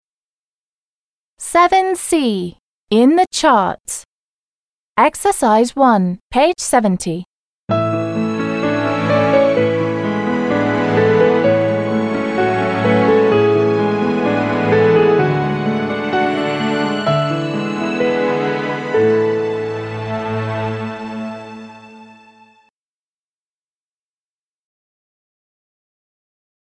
1-H. heavy metal – тяжелый металл
2-E. rap – рэп
3-B. pop – поп
4-G. soul – соул
5-D. classical – классическая музыка
6-А. rock – рок
7-C. jazz – джаз
8-F. funk – фанк